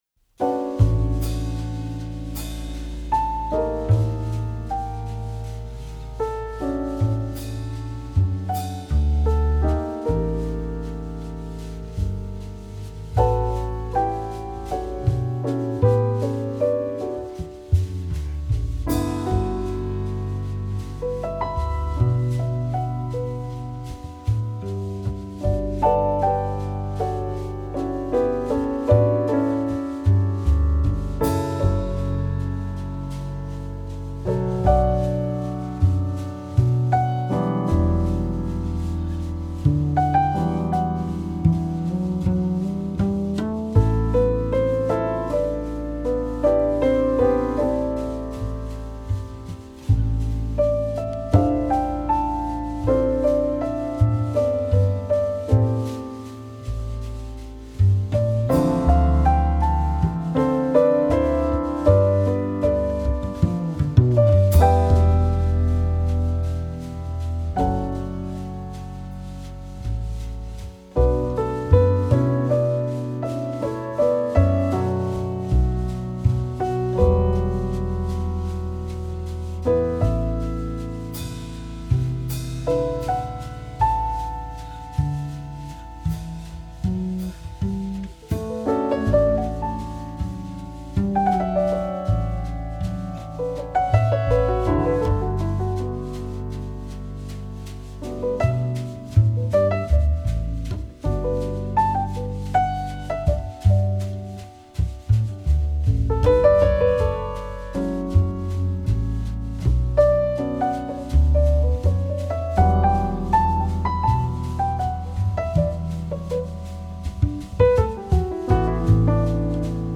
音樂類別 ：爵士樂 ． 爵士三重奏
鋼琴
貝斯
鼓
路西耶三重奏团来演奏，竟然蜕变成最浪漫深情的爵士乐曲，让人陶醉得喜悦忘怀。